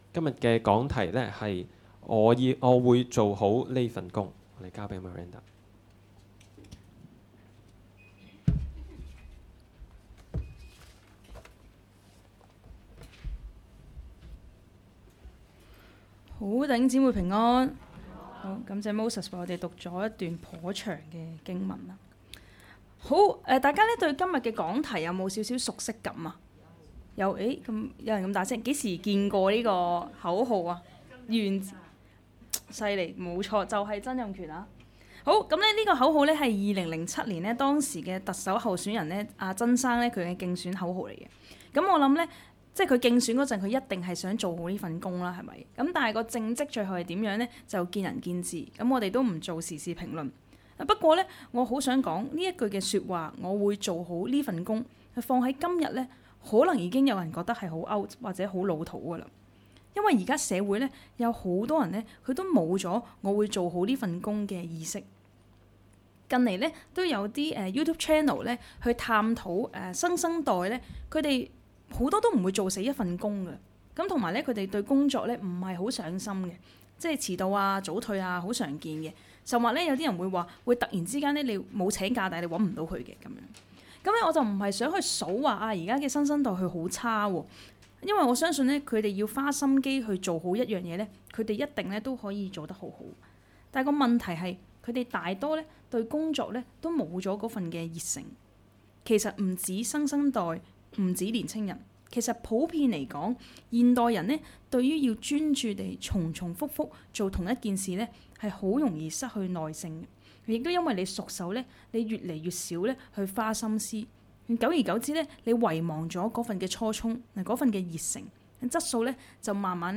講道 ： 我會做好呢份「工」！